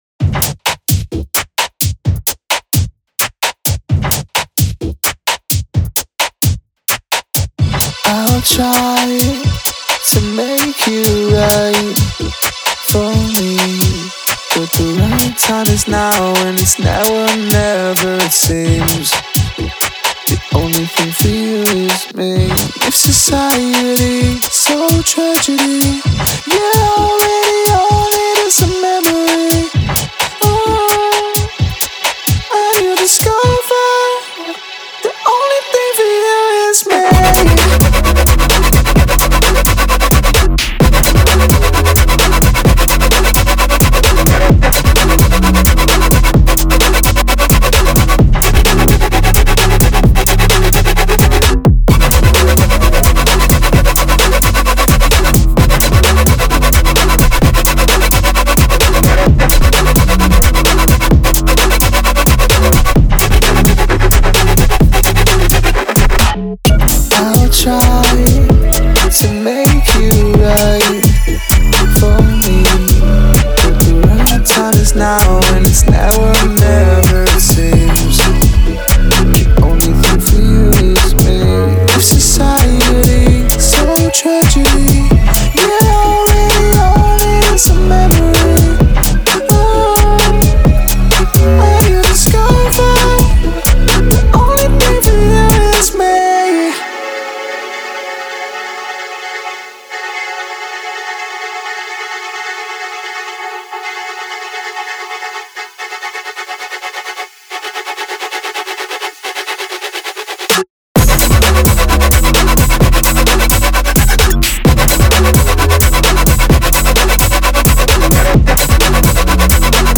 Future House, Dreamy, Euphoric, Glamorous, Quirky